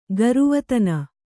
♪ garuvatana